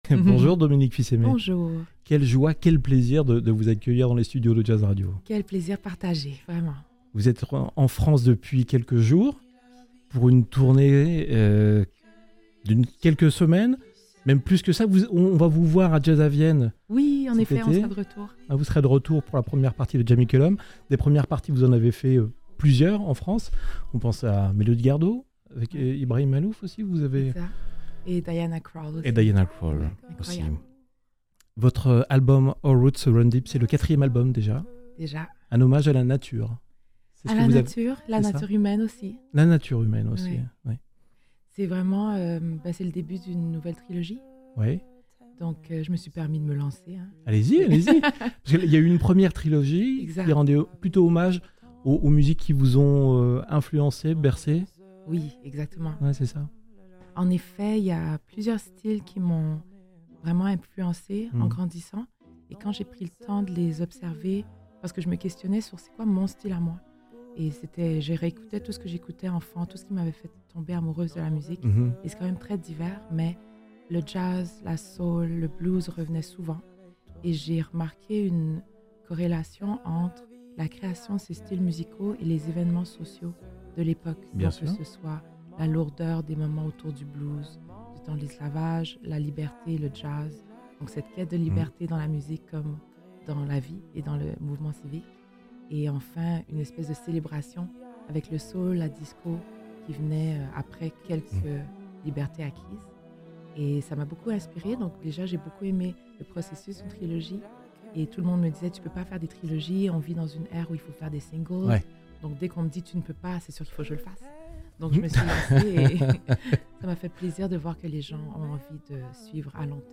Interview Jazz Radio x Docks Live Sessions